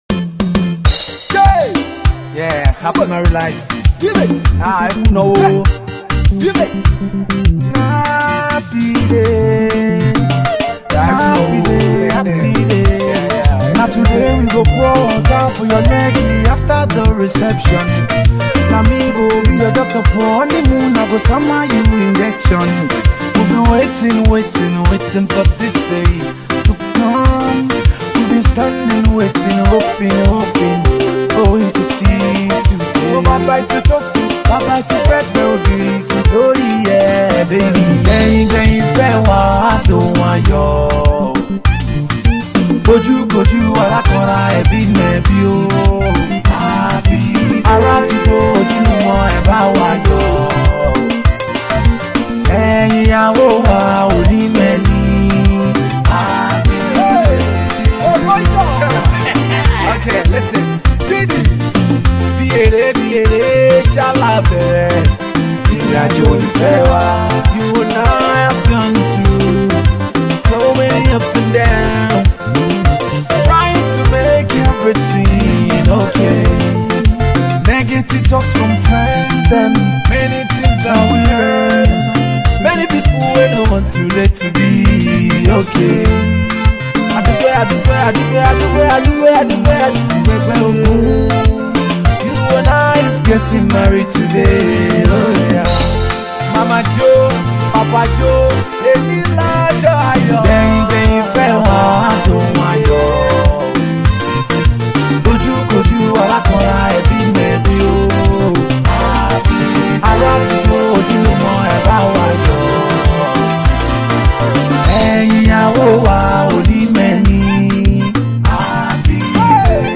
A pretty decent wedding song, this one.